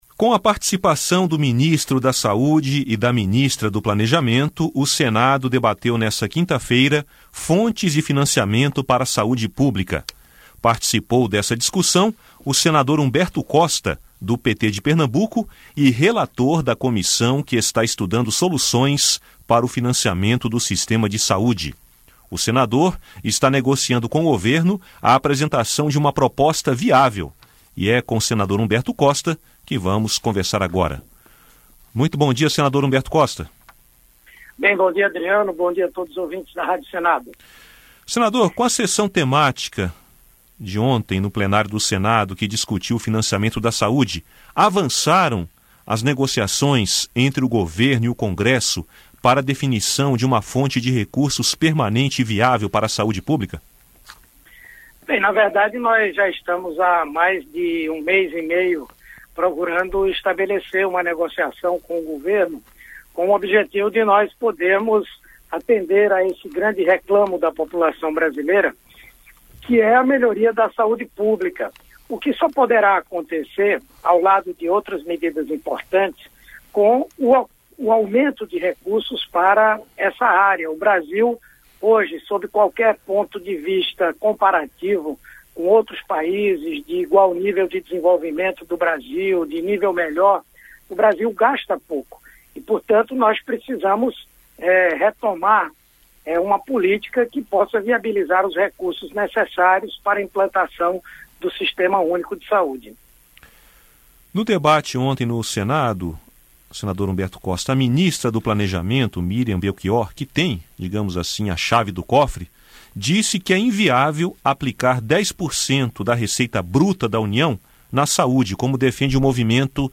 Senado debate financiamento da saúde pública Entrevista com o senador Humberto Costa (PT-PE).